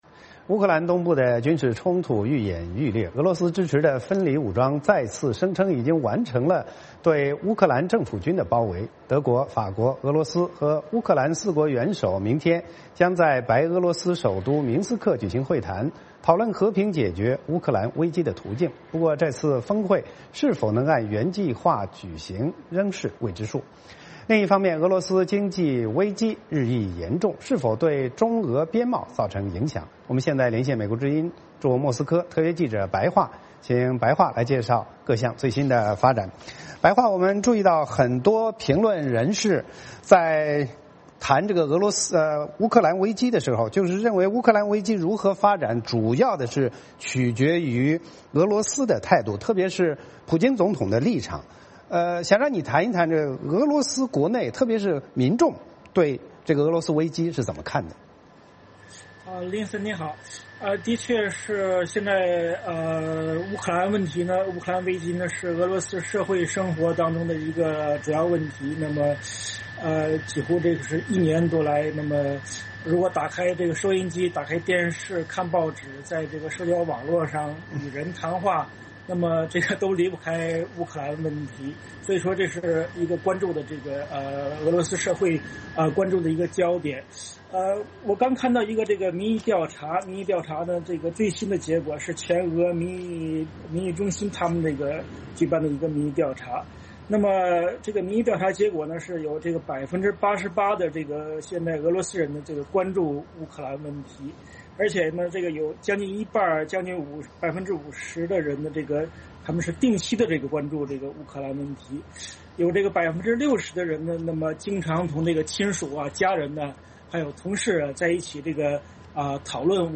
VOA连线：俄罗斯民众如何看乌克兰危机?